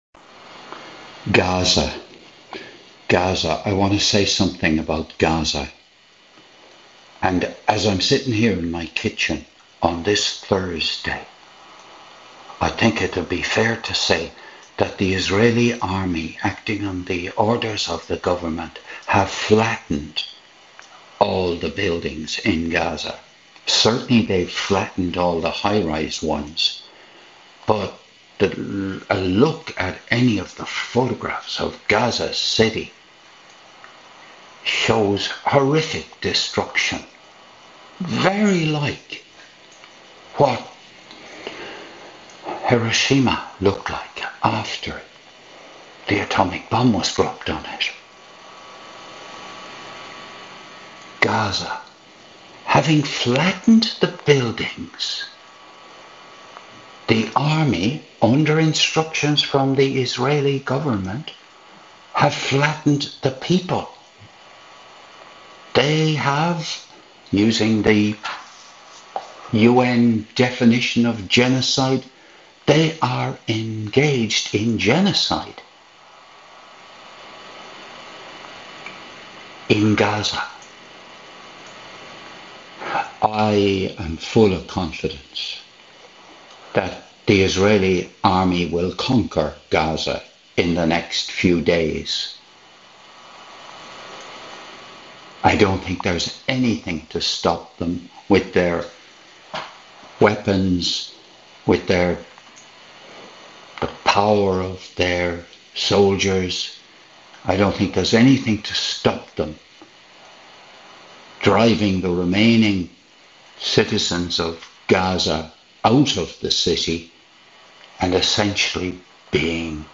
You hear me expressing my feelings about what the. Israeli government has instructed the Israeli army to do in Gaza.